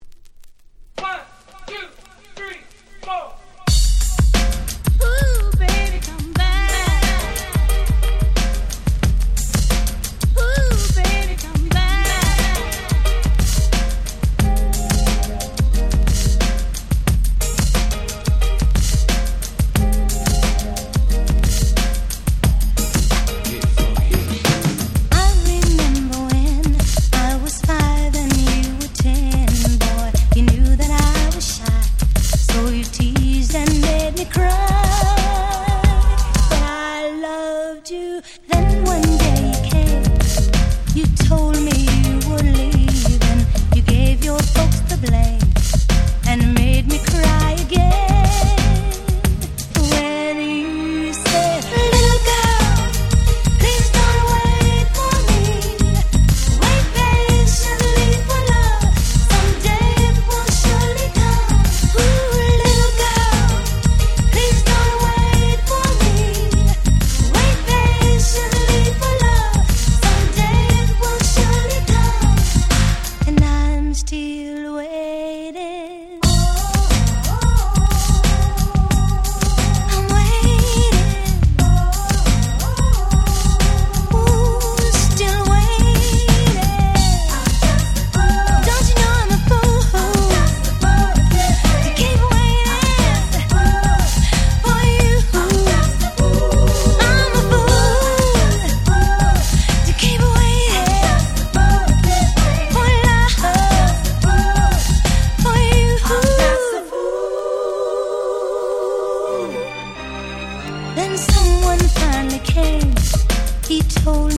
90' Very Nice Remix !!
当時流行りだったGround Beatを用いた柔らかなBeatに彼女の優しいVocalが最高にフィット！！